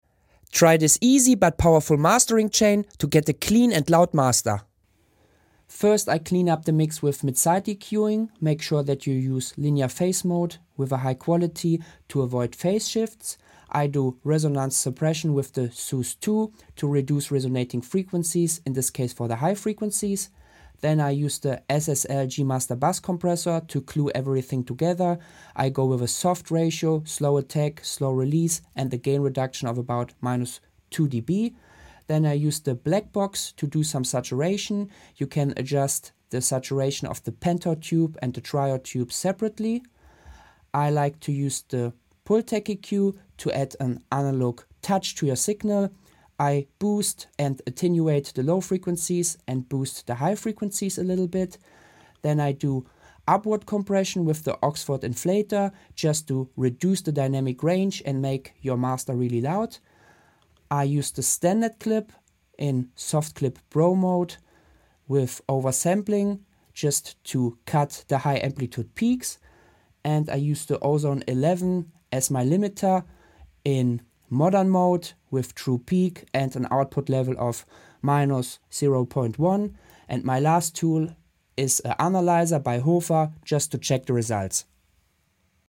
This is a basic but powerful mastering chain and you can reach a pro level sound with this plugins.